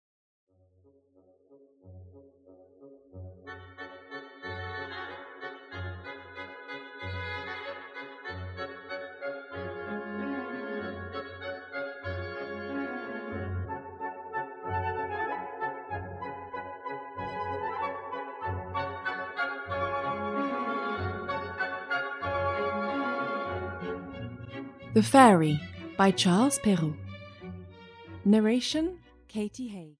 The Fairies, a Fairy Tale (EN) audiokniha
Ukázka z knihy